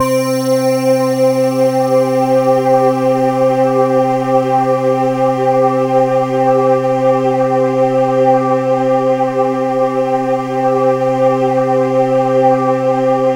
CHIMEPADC4-R.wav